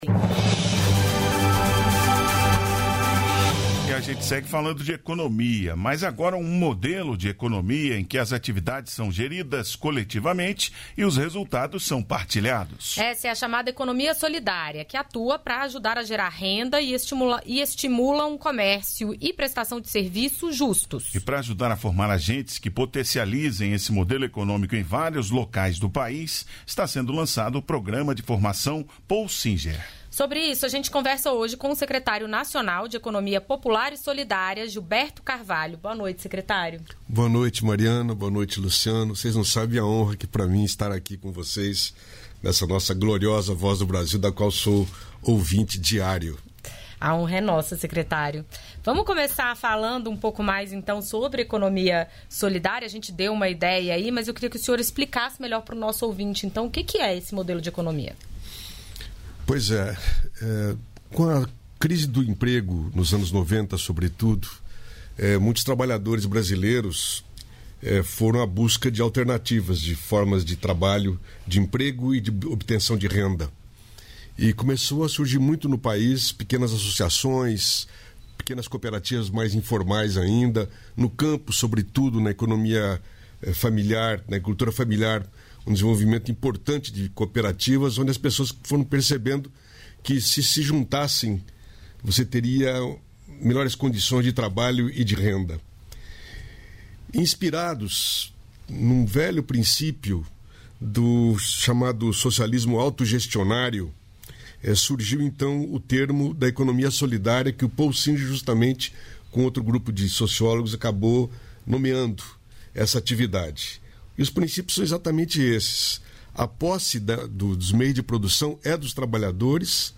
Entrevistas da Voz